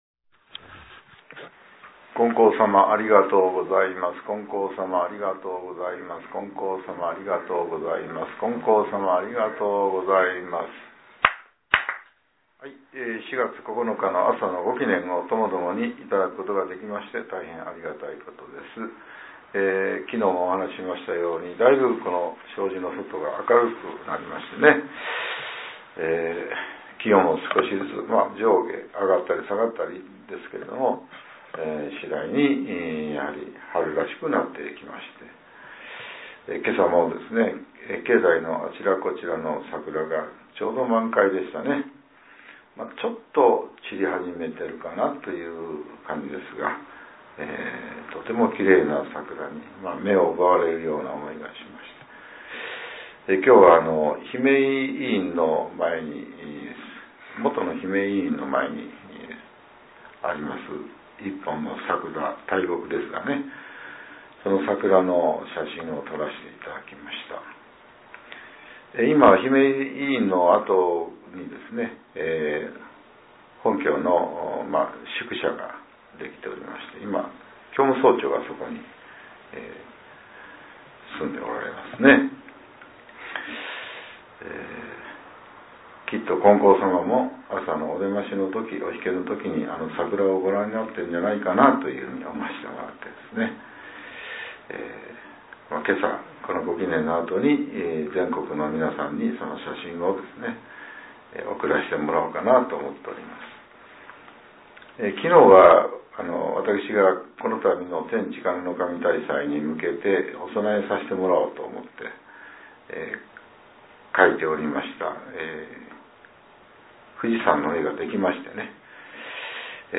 令和７年４月９日（朝）のお話が、音声ブログとして更新されています。